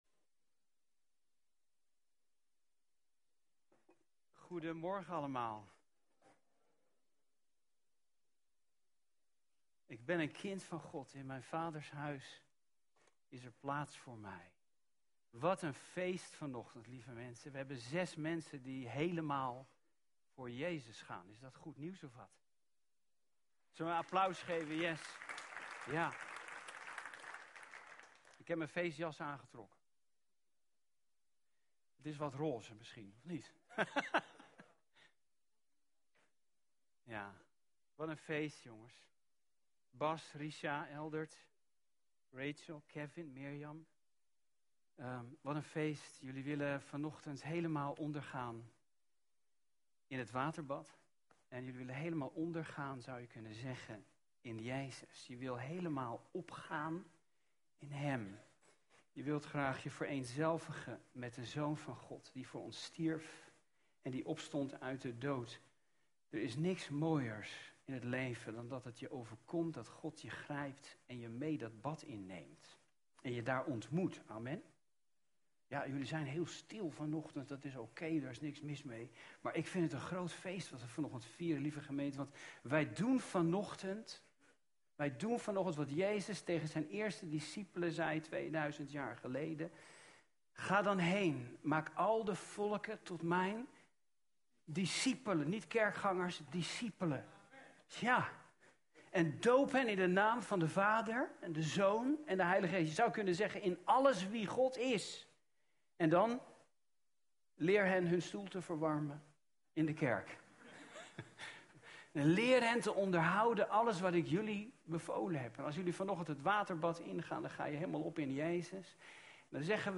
Doopdienst